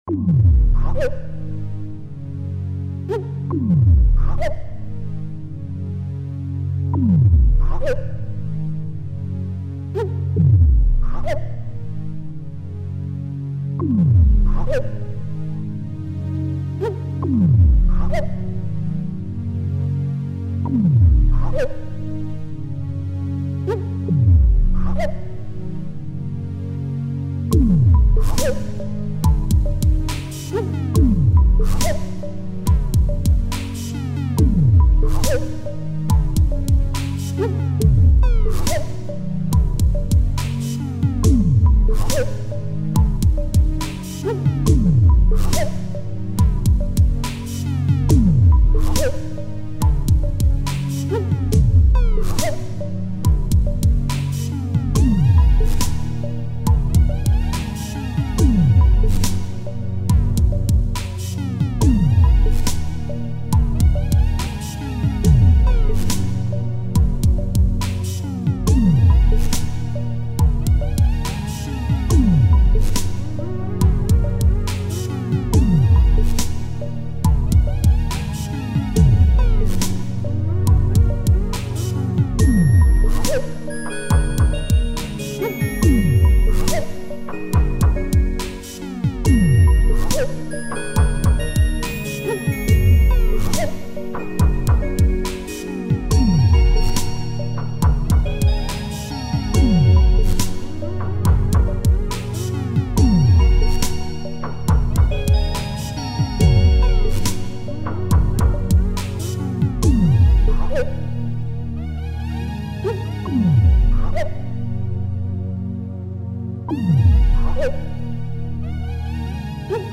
File under: New Electronica